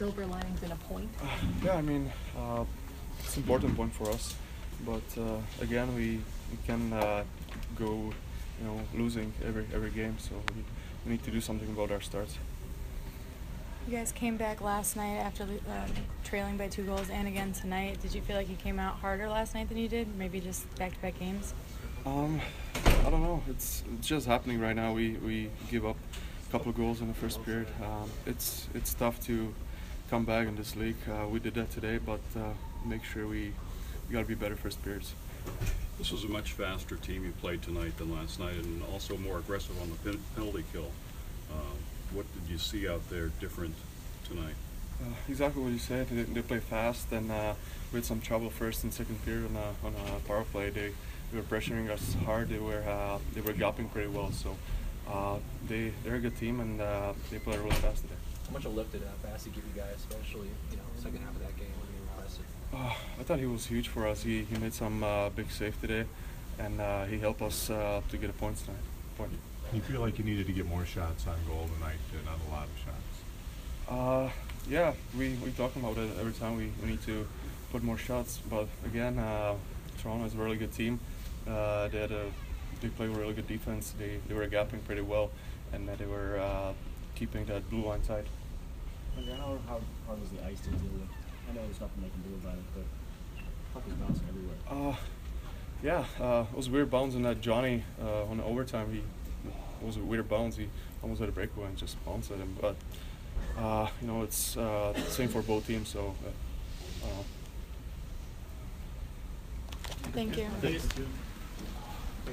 Palat Post Game 12/29